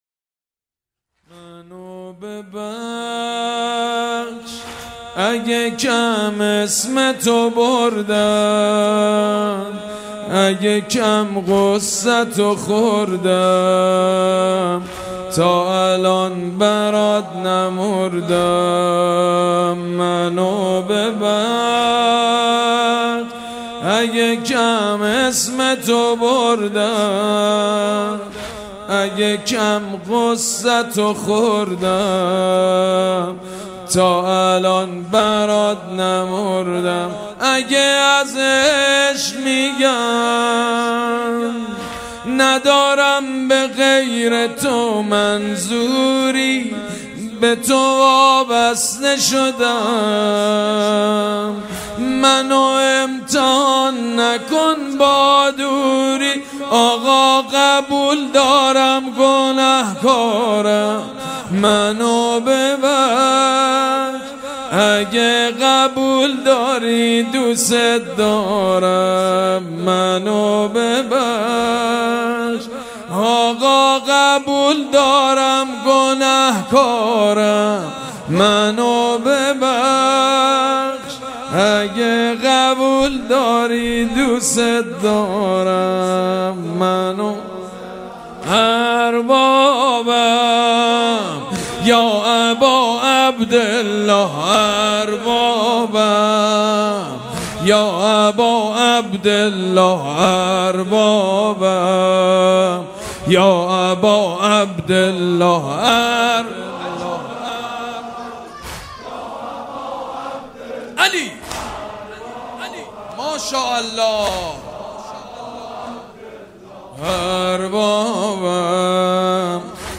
صوت/ عزاداری شب هفتم محرم با نوای سیدمجید بنی‌فاطمه
مـرثـیـه‌خـوانــی
در مجموعه پارک ارم تهران برگزار می گردد